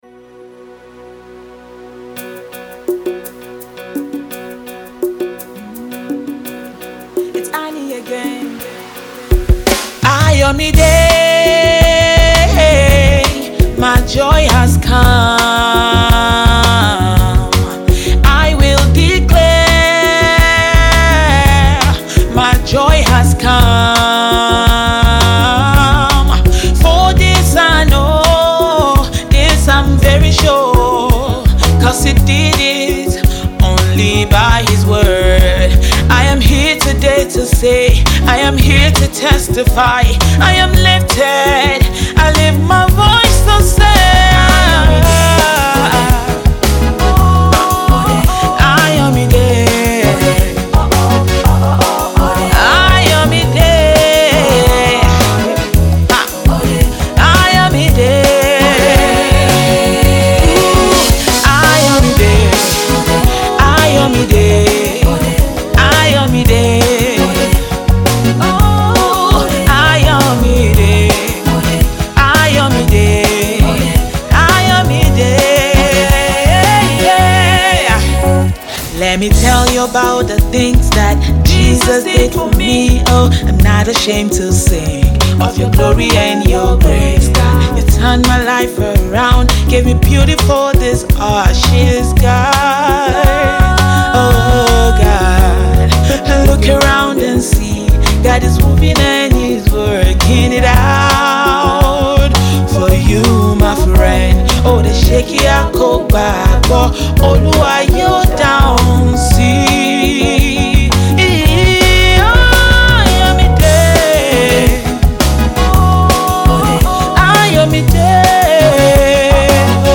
sweet single